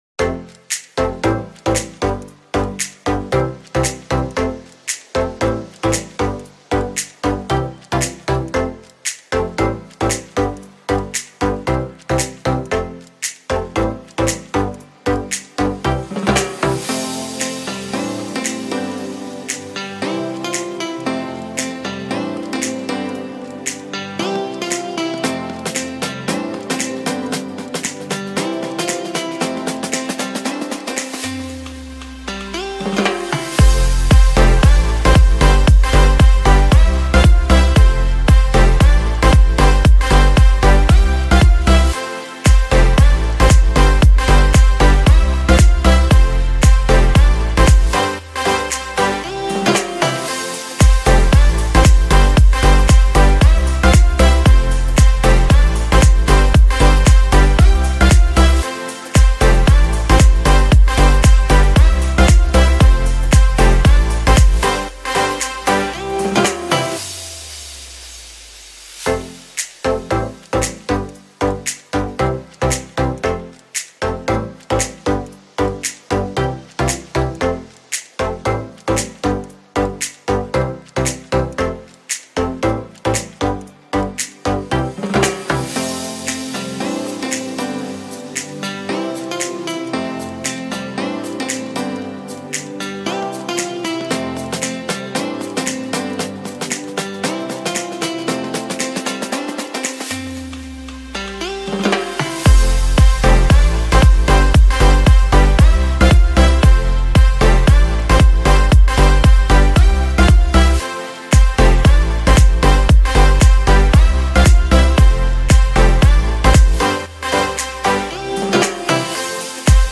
MÚSICA-TROPICAL.mp3